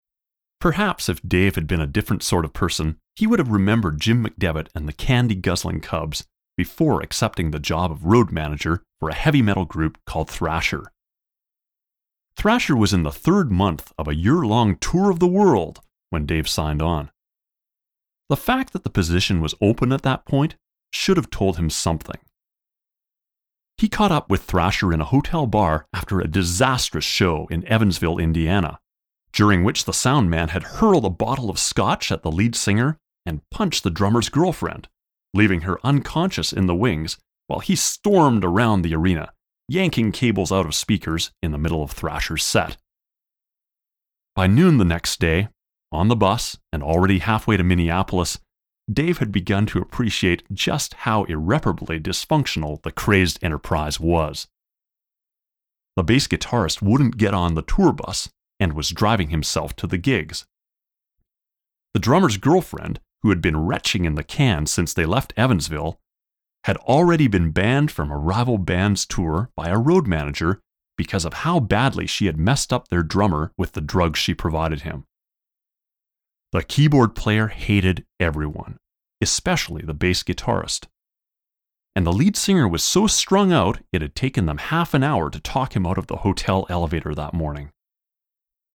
I do audiobooks, commercials, and narration.